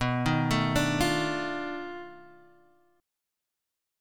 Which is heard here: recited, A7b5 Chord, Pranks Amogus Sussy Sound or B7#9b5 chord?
B7#9b5 chord